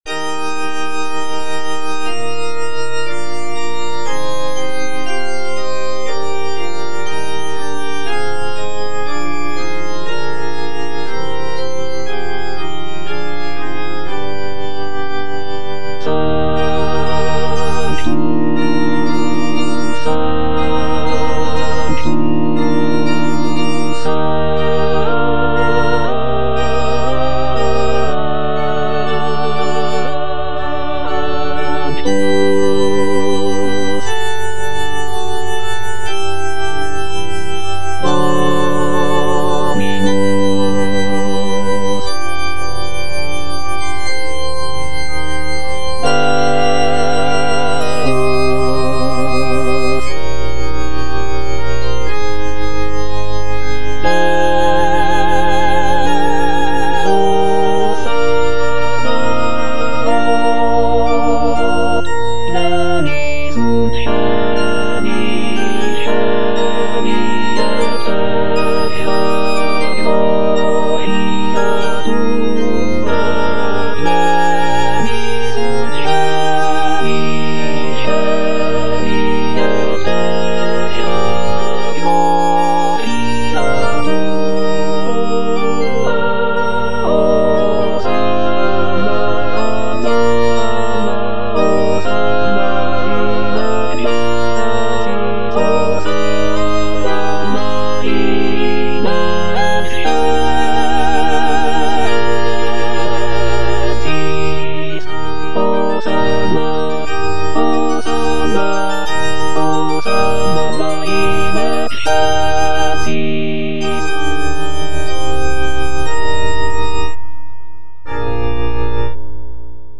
Completed in 1878, this mass is a significant work within Rheinberger's sacred music repertoire. It is composed for SATB choir, soloists, organ, and orchestra.
This composition is known for its rich textures, expressive melodies, and intricate interplay between the vocal and instrumental sections, making it a notable contribution to the genre of sacred choral music.